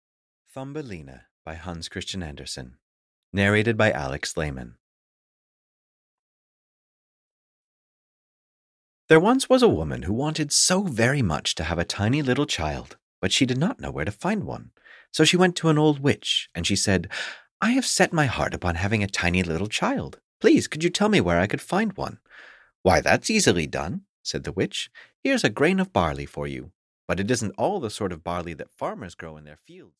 Thumbelina (EN) audiokniha
Ukázka z knihy